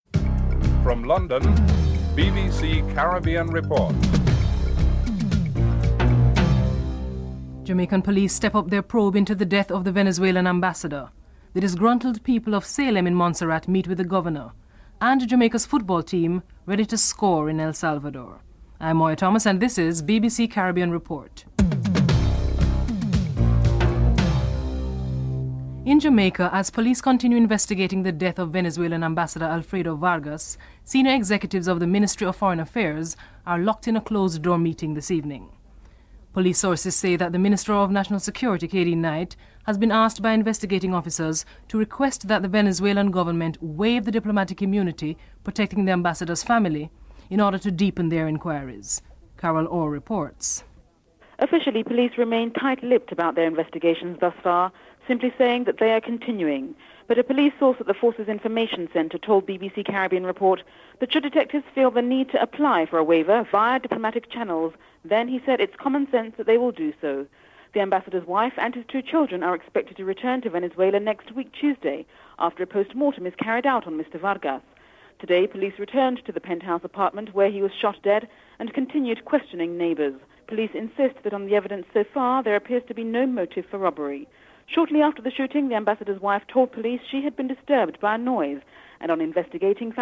1. Headlines (00:00-00:24)
CARICOM's Chief Negotiator, Sir Shridath Rampahl is interivewed (06:13-07:16)